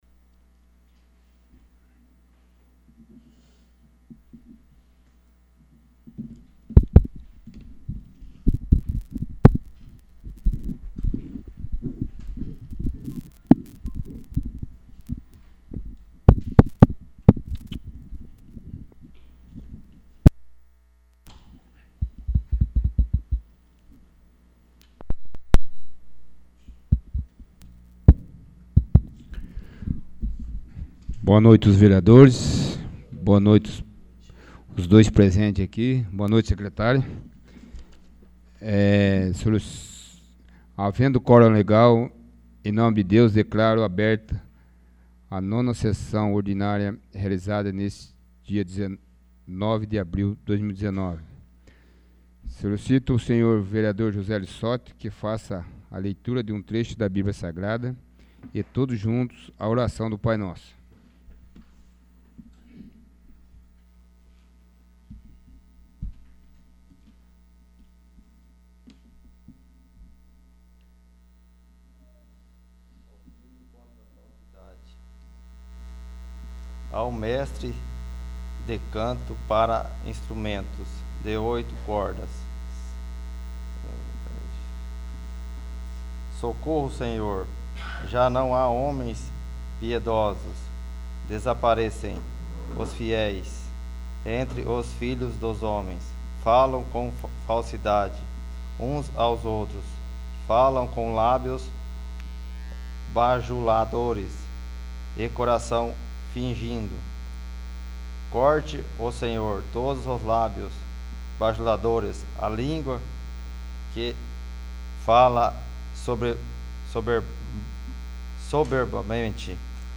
9º. Sessão Ordinária 09/04/2019
9º. Sessão Ordinária